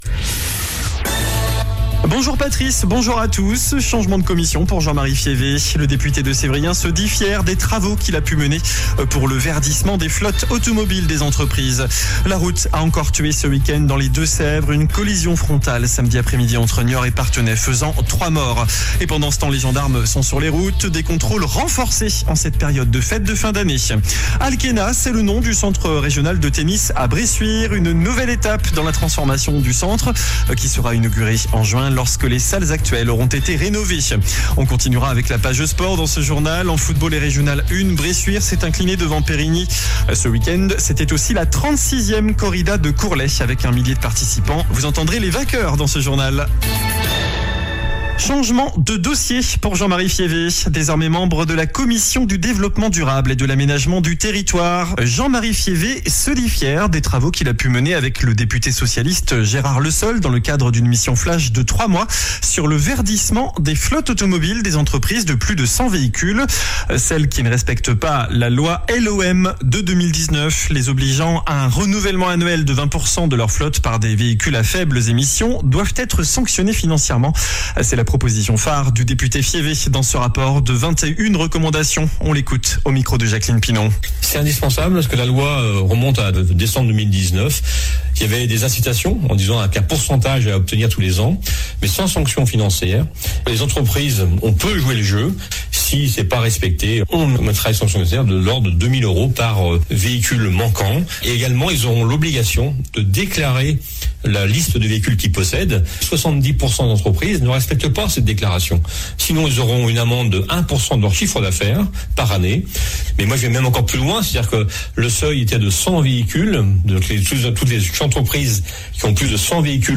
JOURNAL DU LUNDI 23 DECEMBRE ( MIDI )